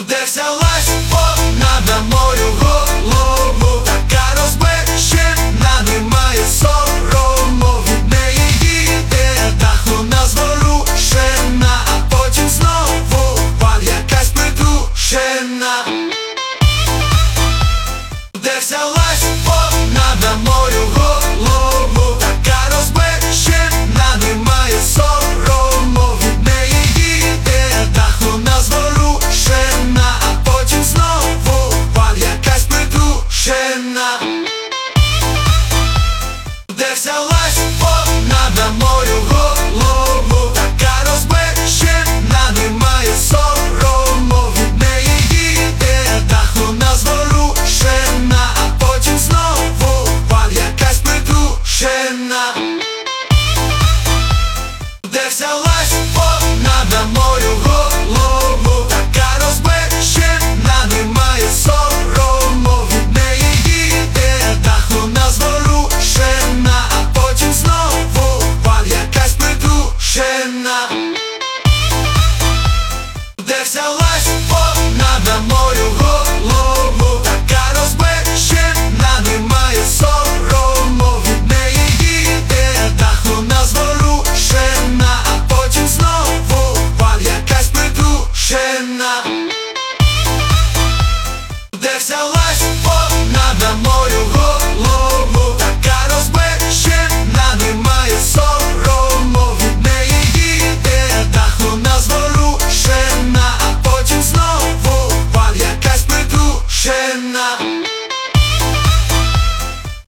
Узбекские песни Слушали